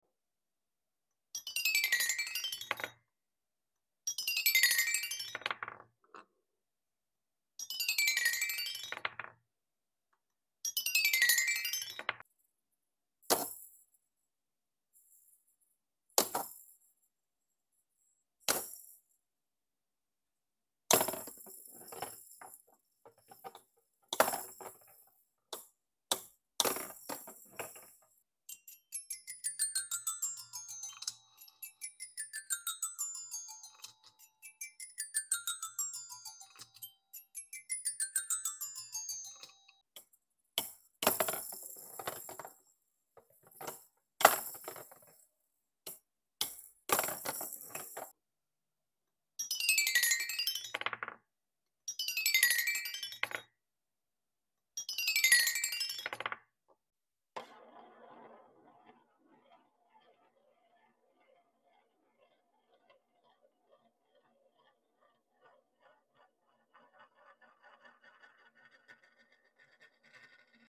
Rainbow Keys Symphony | Relaxing